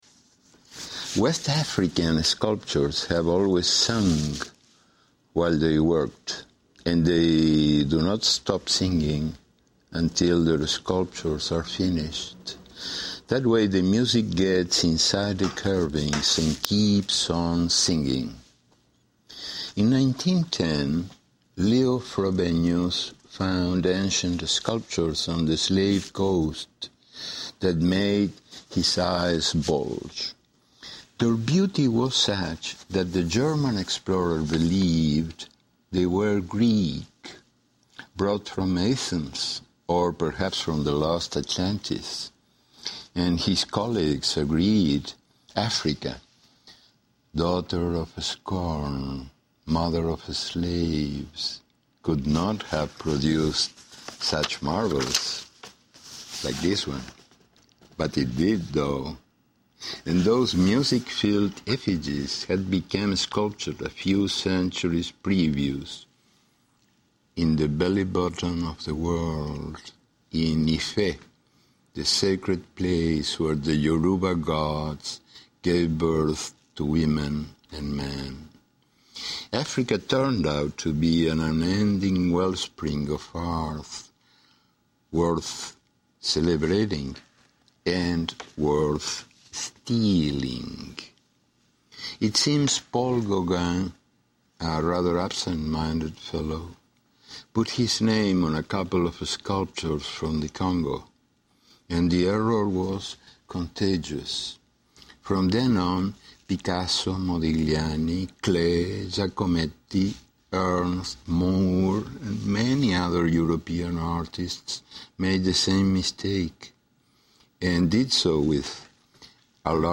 Hoor Galeano het verhaal voorlezen bij …